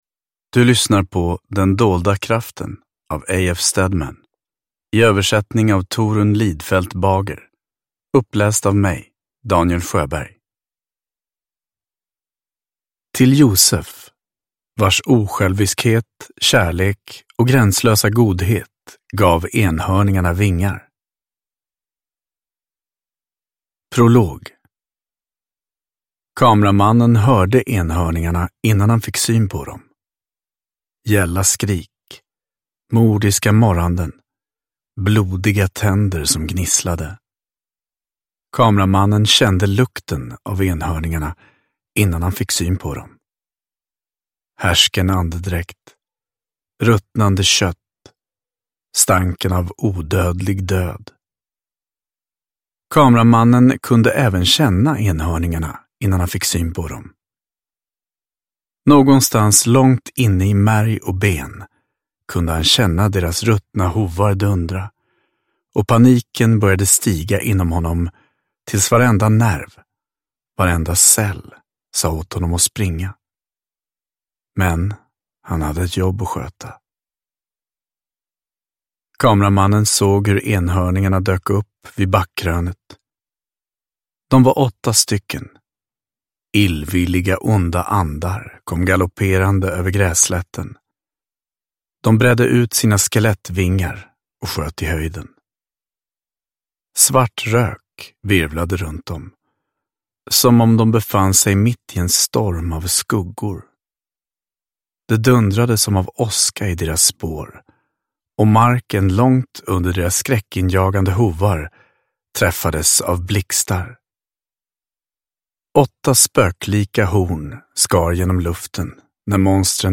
Den dolda kraften – Ljudbok – Laddas ner